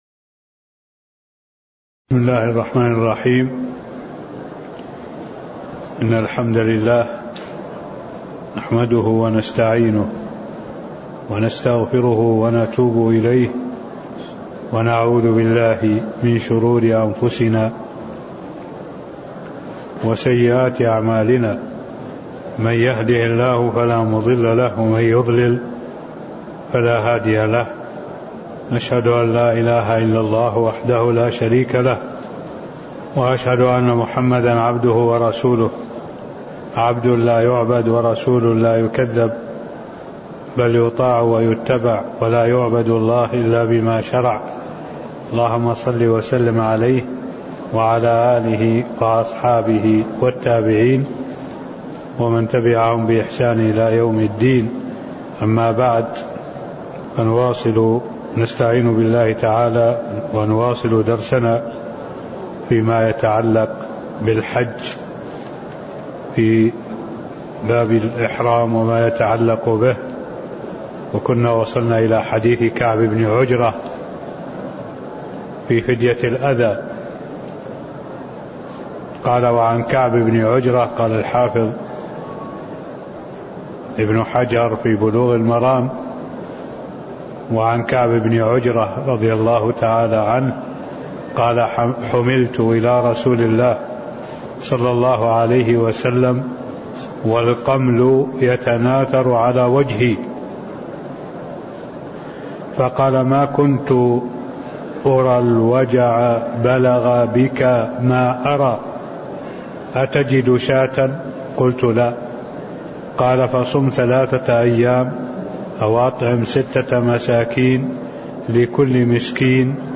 المكان: المسجد النبوي الشيخ: معالي الشيخ الدكتور صالح بن عبد الله العبود معالي الشيخ الدكتور صالح بن عبد الله العبود تكملة باب وجوب الإحرام وصفته (04)